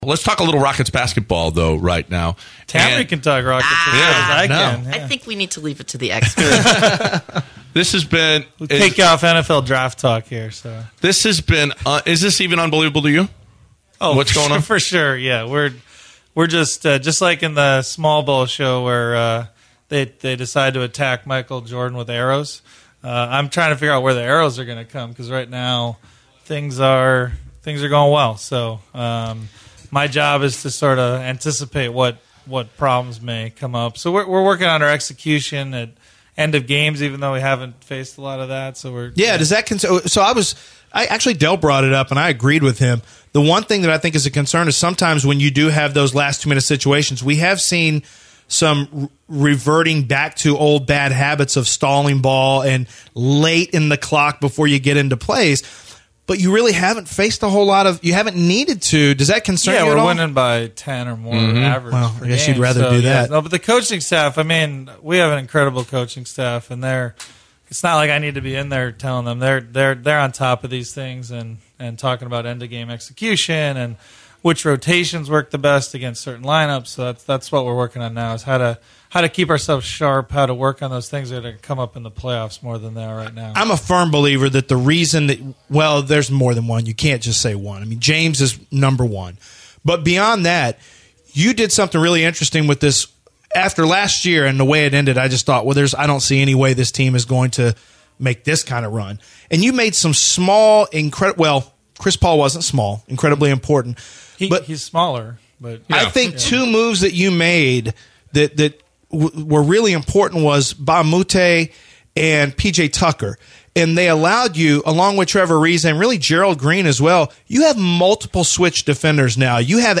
Rockets GM Daryl Morey joins The Bench and talks about the Rockets' historic season.